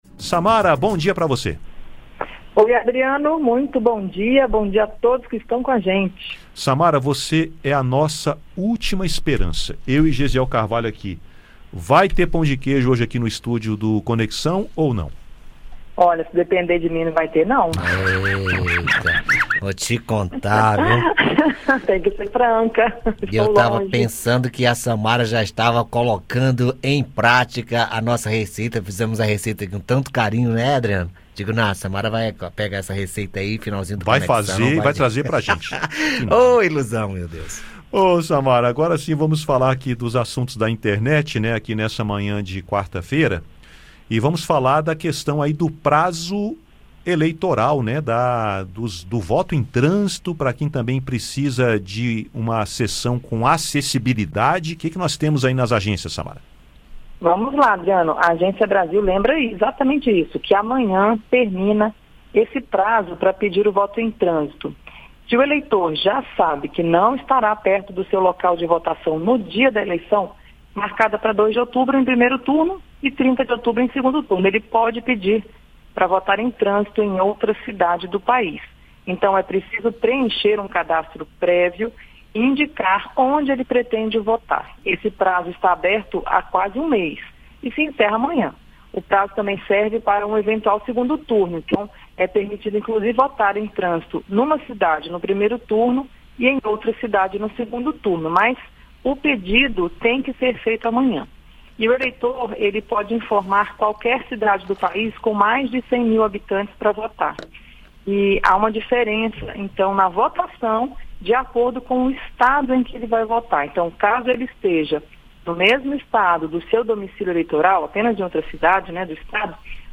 Detalhes e outras informações com a jornalista